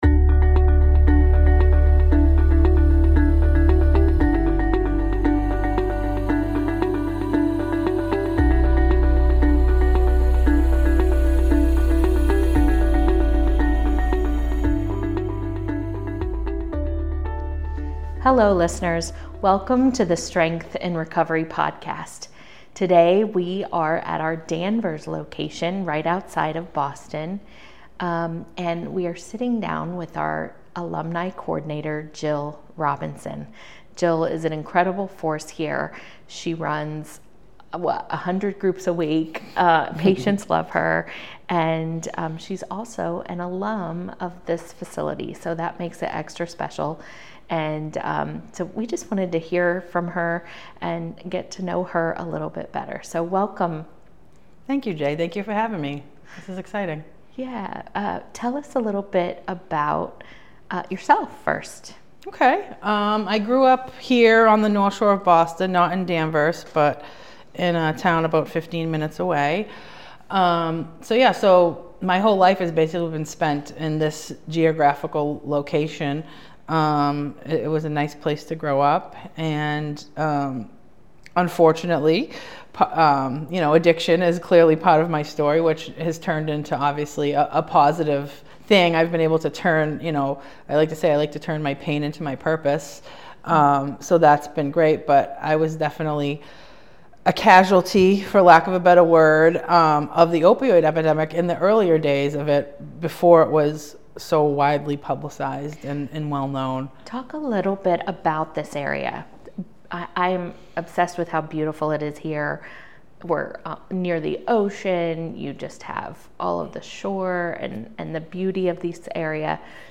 M.A. Each episode explores the journey of recovery through candid conversations, touching down on important topics such as trauma, grief, coping, and relapse.